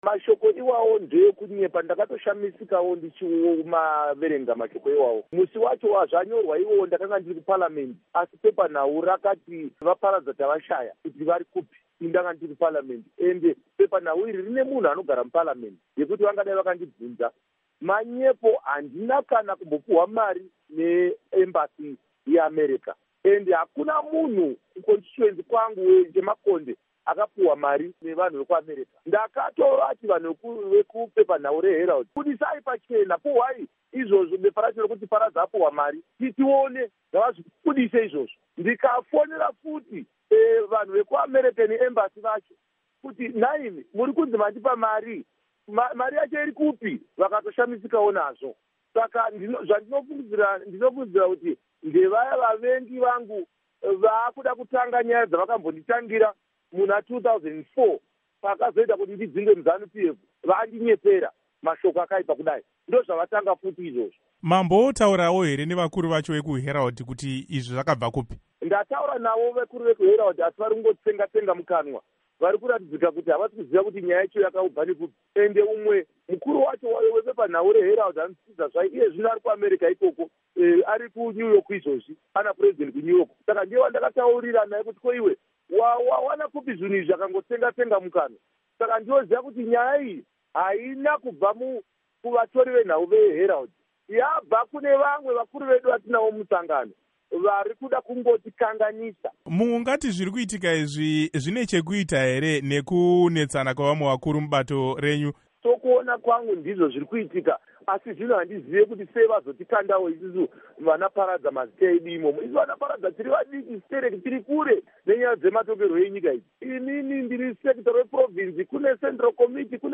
Embed share Hurukuro naVaKindness Paradza by VOA Embed share The code has been copied to your clipboard.